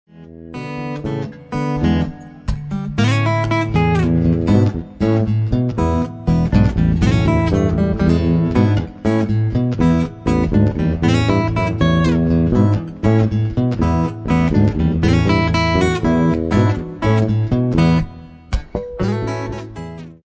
chitarra acustica
sitar